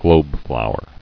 [globe·flow·er]